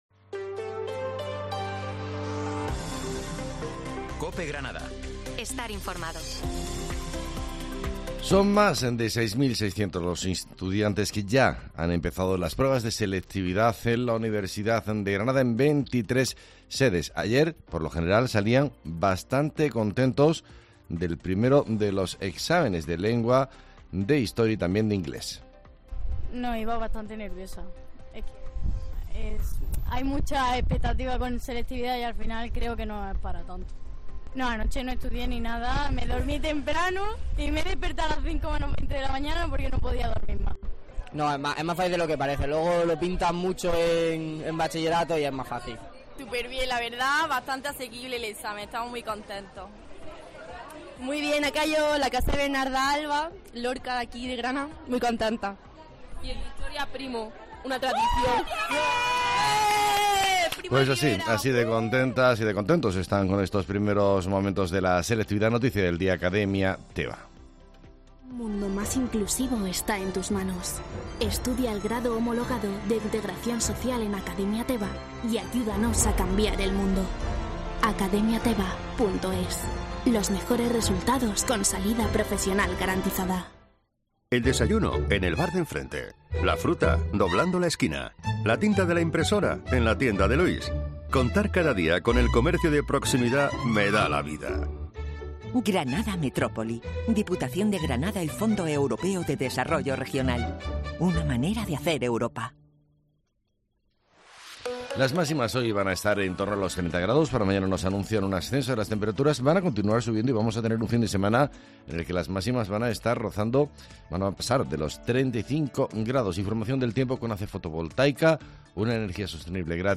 Herrera en COPE Informativo del 14 de junio